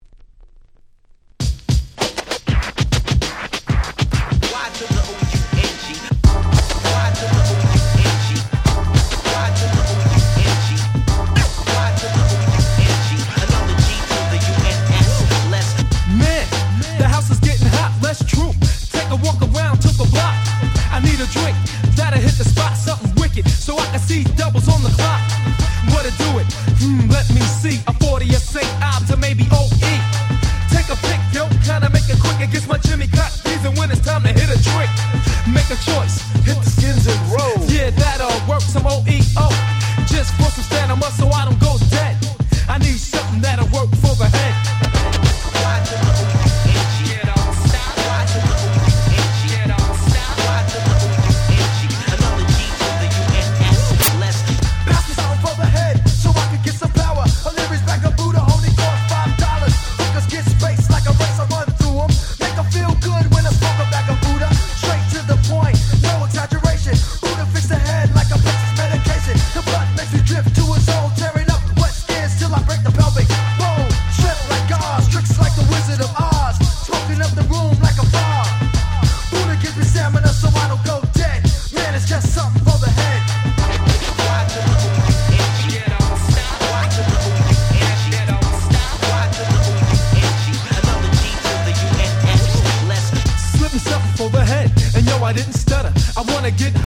93' Nice Hip Hop !!